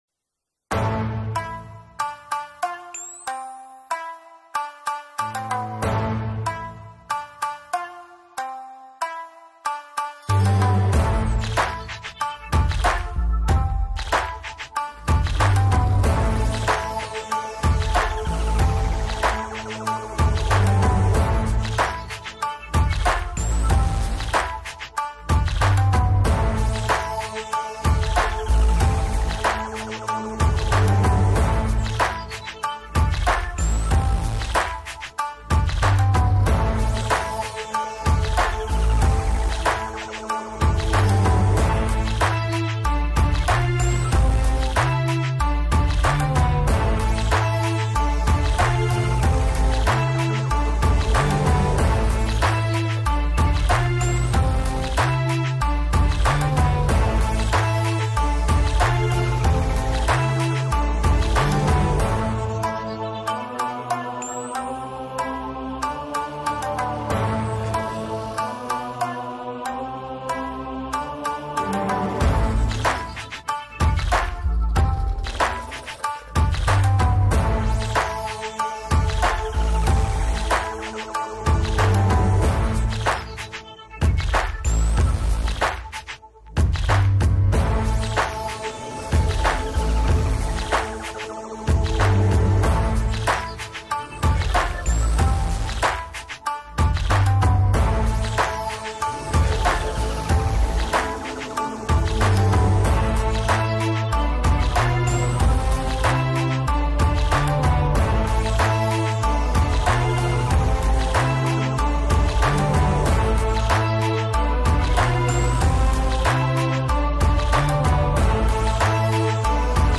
фоновую музыку